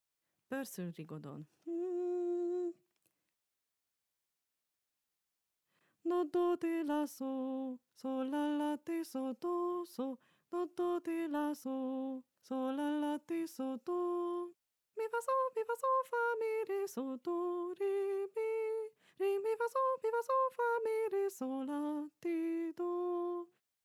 Régi barokk táncok és új stílusú magyar népdalok, táncdallamok